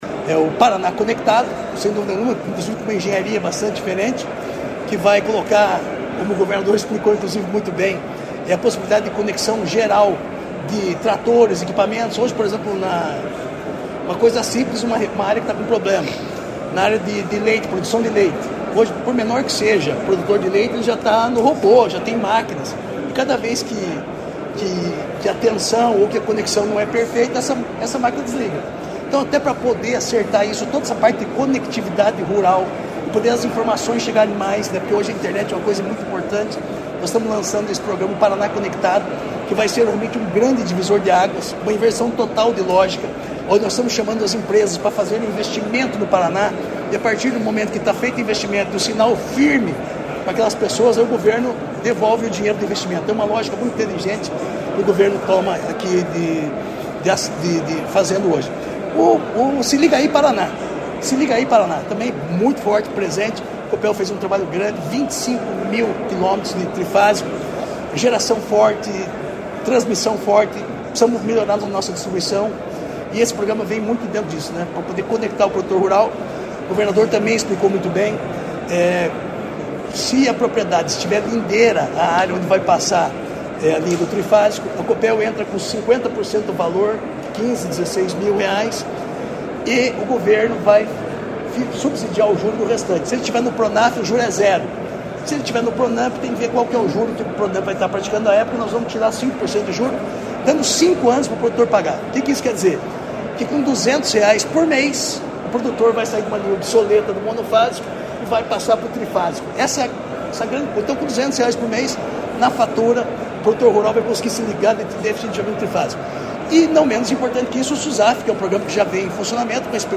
Sonora do secretário da Agricultura e do Abastecimento, Marcio Nunes, sobre os programas Se Liga Aí Paraná e Paraná Conectado